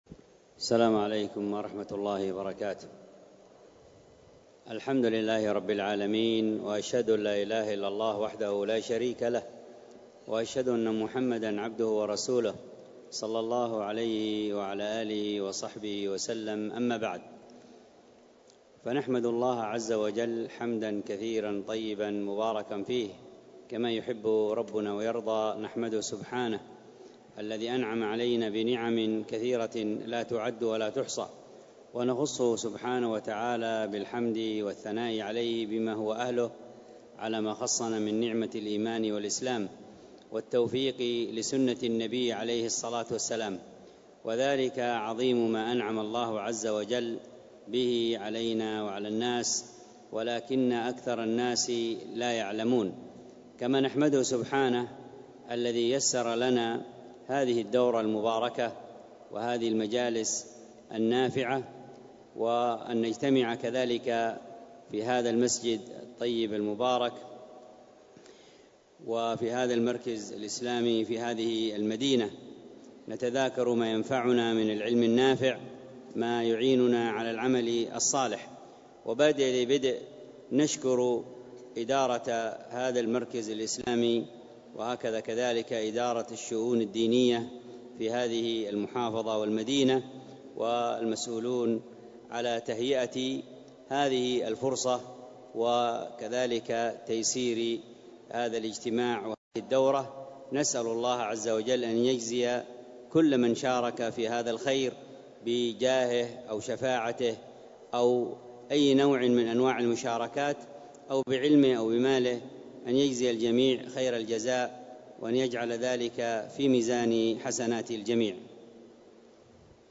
محاضرة مترجمة إلى الاندونيسية والتي كانت بمسجد المركز الإسلامي بمدينة بالكبابان بإندونيسيا